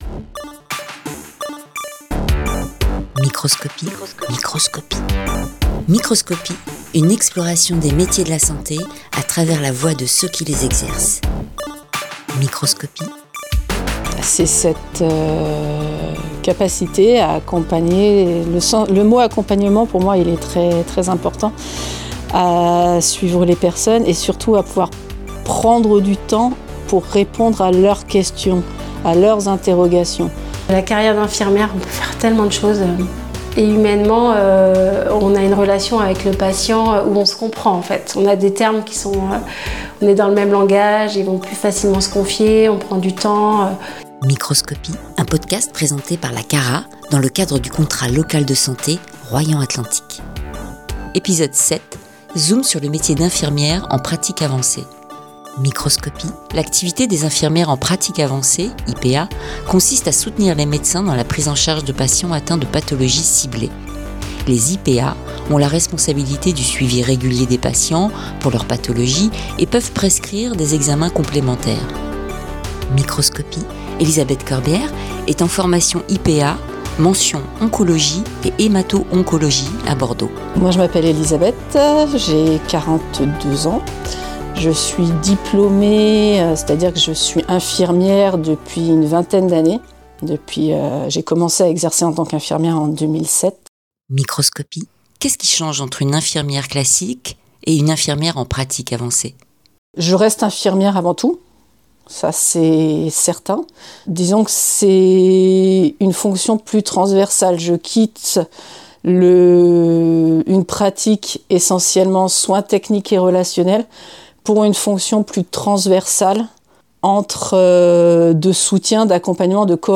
« Microscopie », c’est la nouvelle série de podcasts qui vous plonge au cœur des métiers de la santé, racontés par celles et ceux qui les vivent au quotidien.
À travers des témoignages sincères de professionnels en poste ou en formation sur le territoire de Royan Atlantique, partez à la découverte d’un univers aussi passionnant que méconnu.